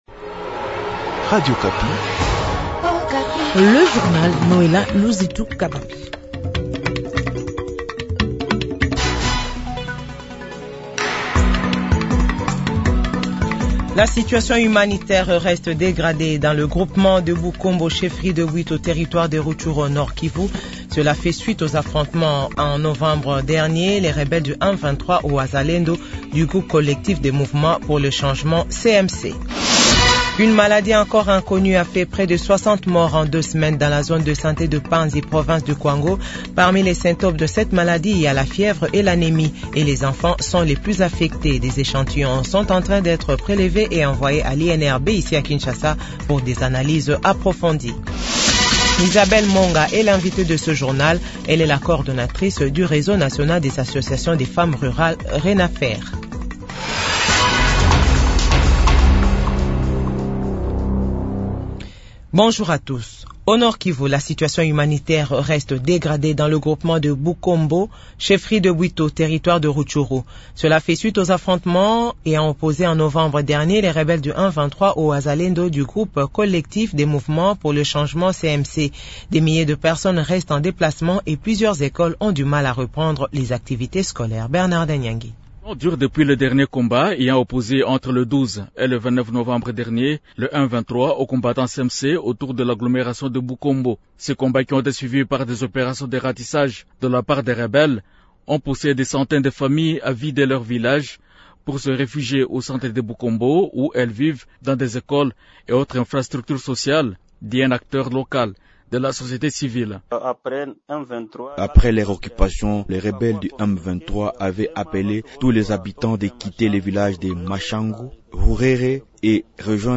JOURNAL FRANÇAIS DE 15H00